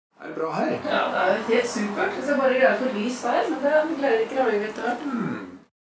Nedenfor kan du høre forskjellen når både høyre og venstre lyd kommer samtidig, og når høyre lyd er litt forsinket.
Lyden fra høyre øre er forsinket med 25 millisekunder (0,025 sekund)
I eksemplene ovenfor er de forskjellige deler av lydenes toneområde forsinket like mye.